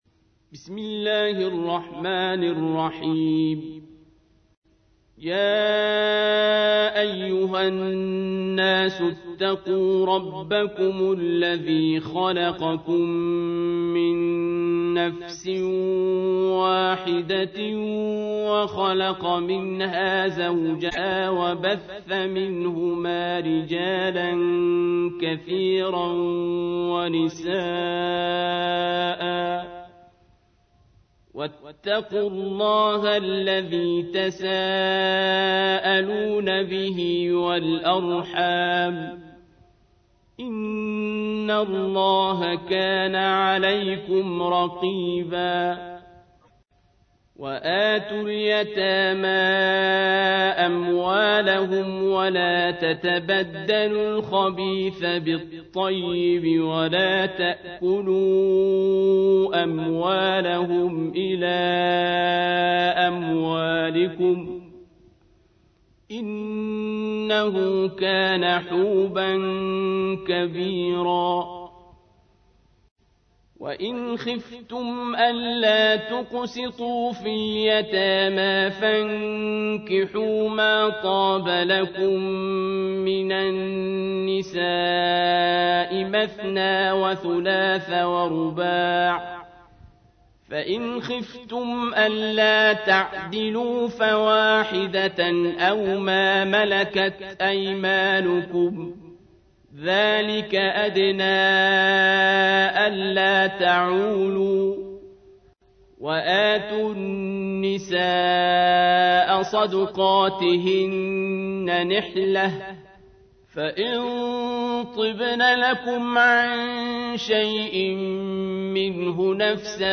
تحميل : 4. سورة النساء / القارئ عبد الباسط عبد الصمد / القرآن الكريم / موقع يا حسين